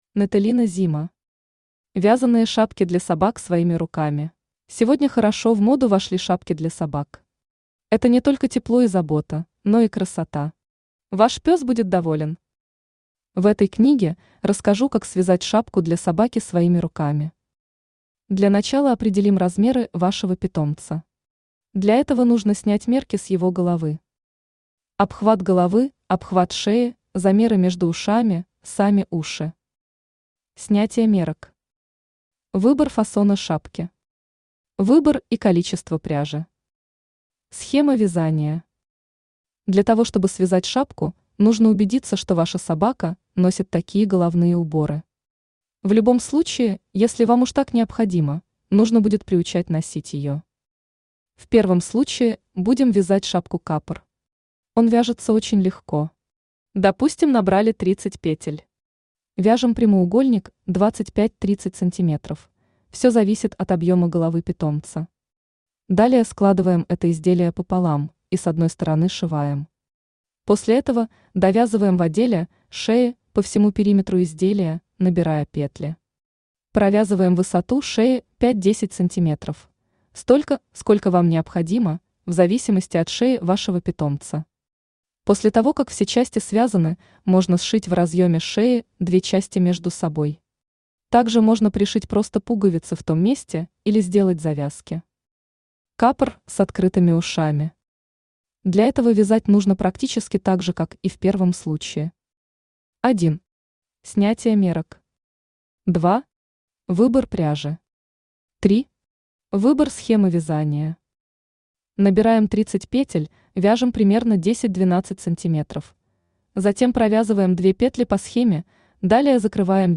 Аудиокнига Вязаные шапки для собак своими руками | Библиотека аудиокниг
Aудиокнига Вязаные шапки для собак своими руками Автор Natalina Zima Читает аудиокнигу Авточтец ЛитРес.